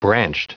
Prononciation du mot : branched